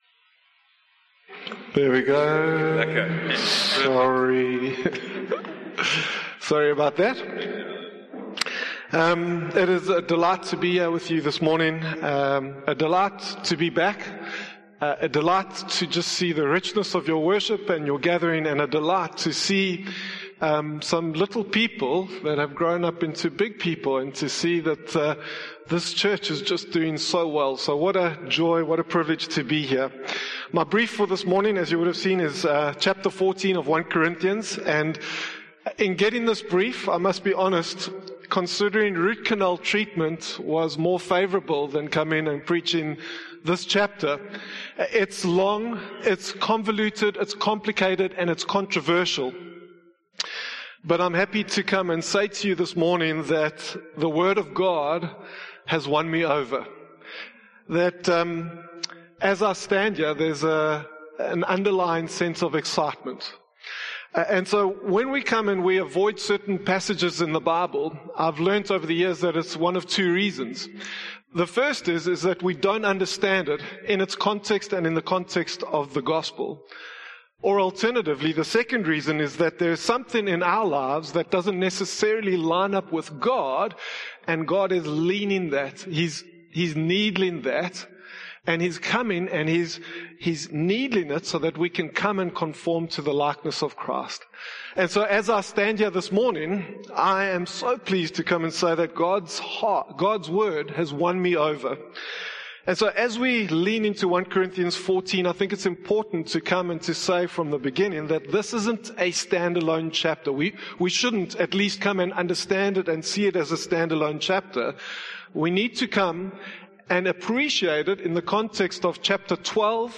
The sermon compares prophecy, which builds up the church in a common language, to tongues, which are personal and often unintelligible without an interpreter.